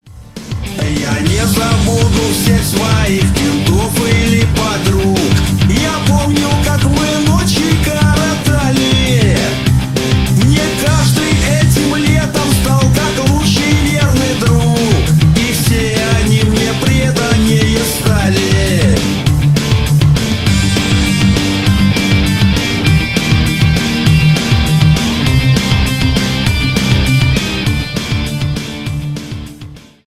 рок
нейросеть